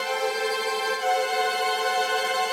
Index of /musicradar/gangster-sting-samples/95bpm Loops
GS_Viols_95-A2.wav